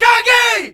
All Punjabi Vocal Pack